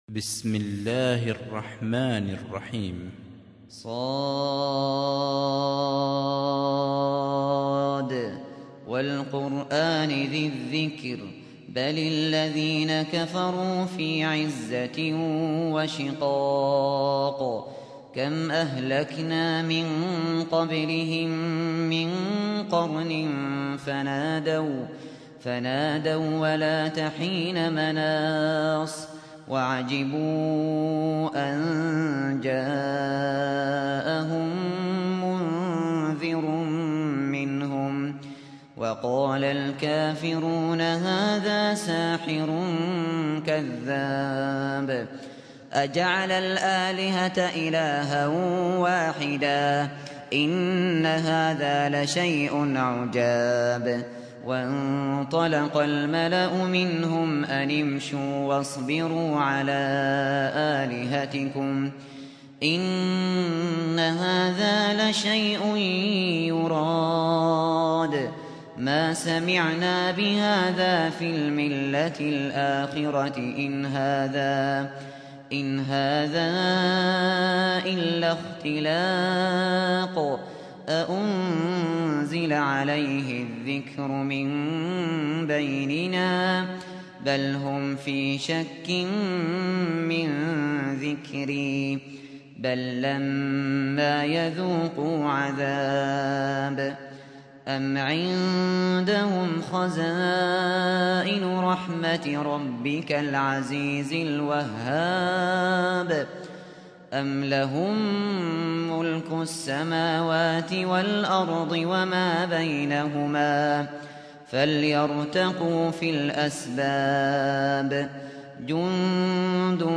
سُورَةُ ص بصوت الشيخ ابو بكر الشاطري